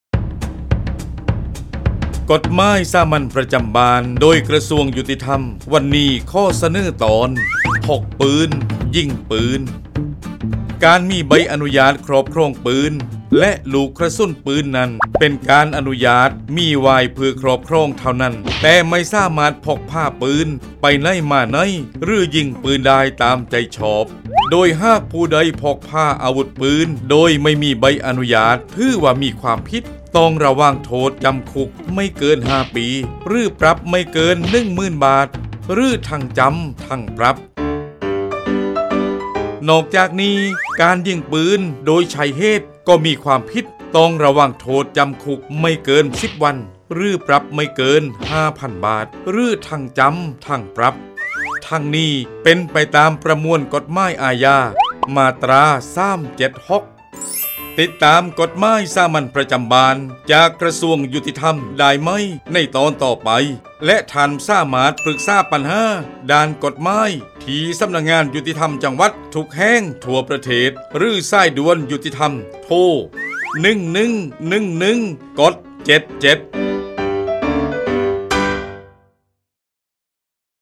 กฎหมายสามัญประจำบ้าน ฉบับภาษาท้องถิ่น ภาคใต้ ตอนพกปืน ยิงปืน
ลักษณะของสื่อ :   บรรยาย, คลิปเสียง